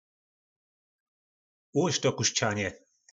Slovník nářečí Po našimu
Uložit - Ułožič 🔉